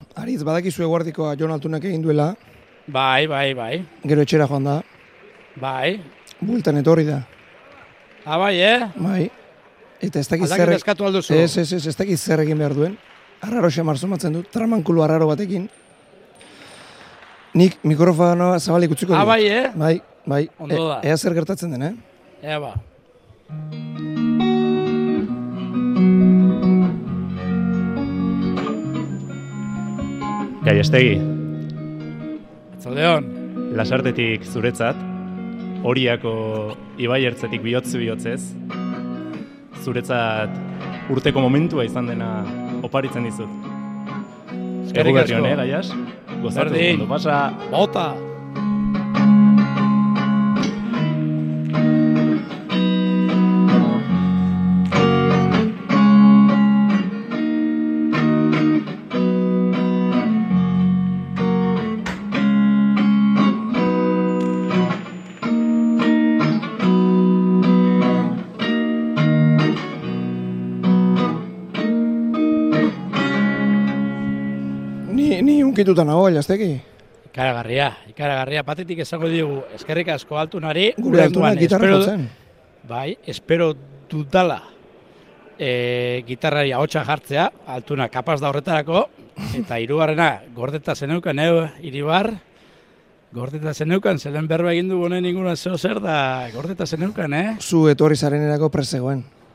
gitarra saioa